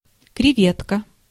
Ääntäminen
Ääntäminen Tuntematon aksentti: IPA: /krʲɪˈvʲetkə/ Haettu sana löytyi näillä lähdekielillä: venäjä Käännös Ääninäyte Substantiivit 1. crevette {f} France Translitterointi: krevetka.